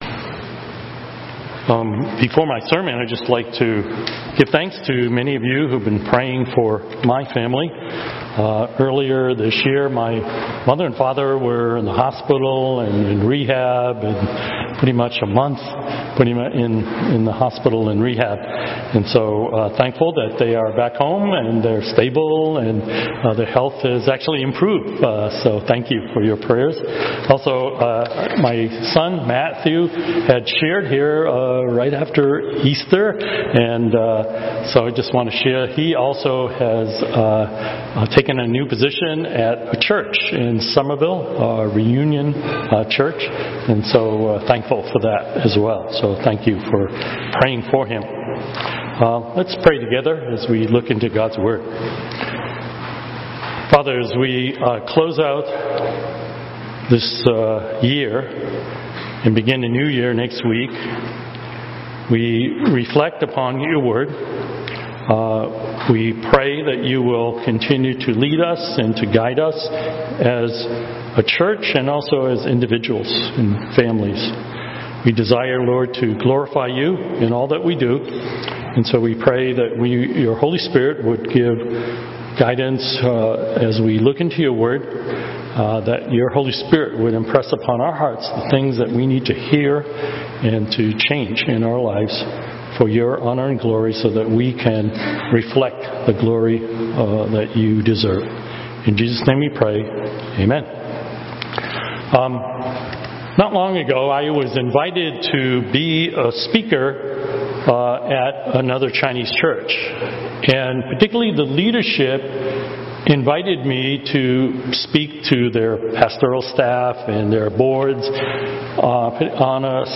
Newton English Service (11:00am) - Page 12 of 34 | Boston Chinese Evangelical Church